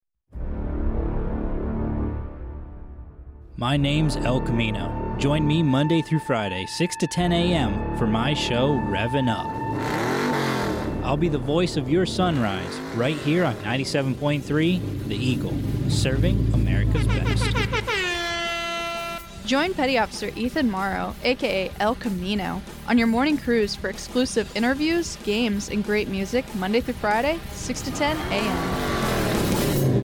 radio showThe EagleAFNpromo